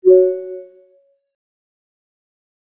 Voice_Confirmation.wav